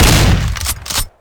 shotgun_fire.ogg